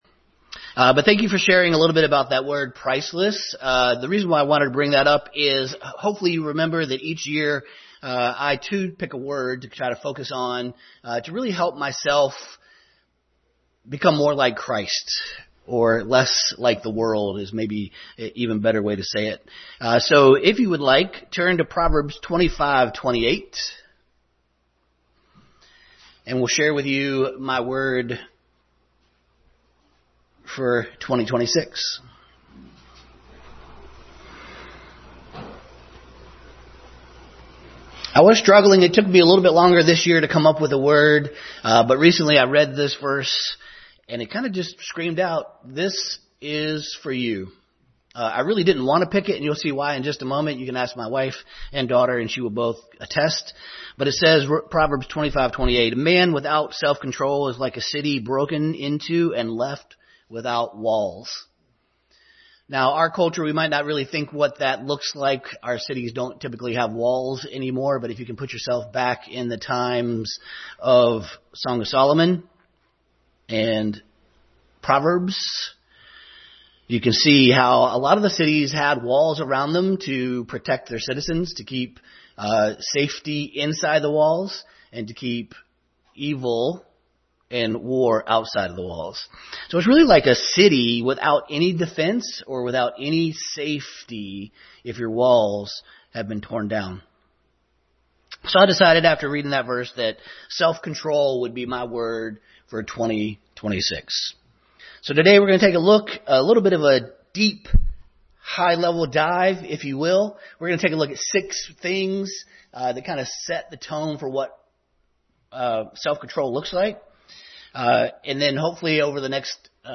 Self Control Passage: Proverbs 25:29, 2 Peter 1:5-8, Galatians 5:22, Romans 7:21-25, 8:1, 13:13, Ephesians 2:8-9, 1 Corinthians 9:24-27, Hebrews 12:1-2 Service Type: Family Bible Hour